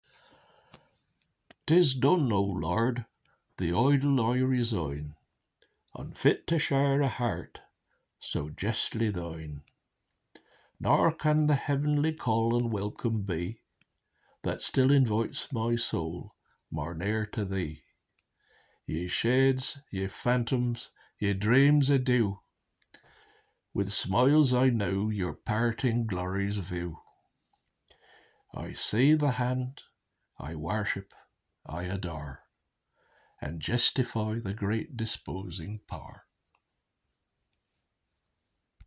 Readings from Franklin’s Poor Richard’s Almanack and his Reformed Mode of Spelling